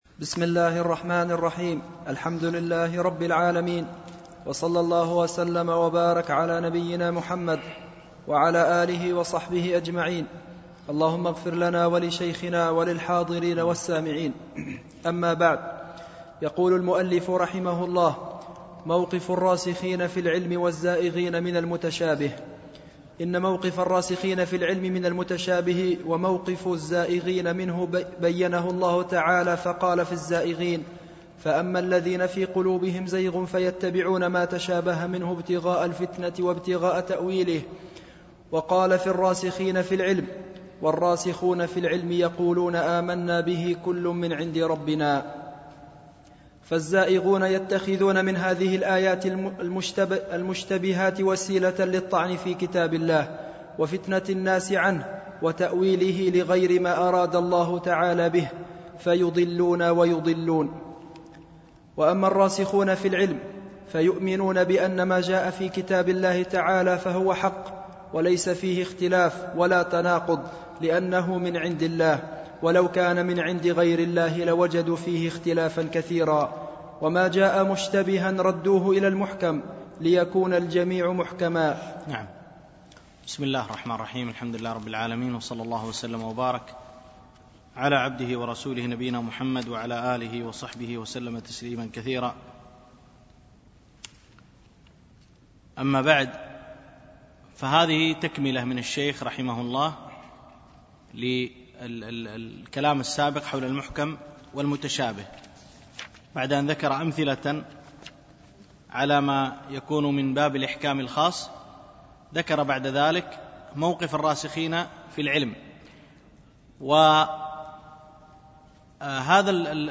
دروس مسجد عائشة
MP3 Mono 22kHz 32Kbps (CBR)